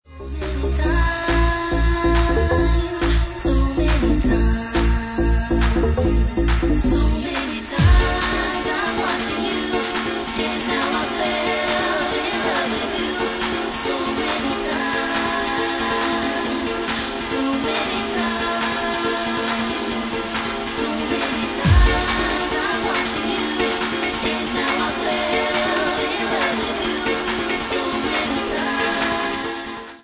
Genre:Bassline House
70 bpm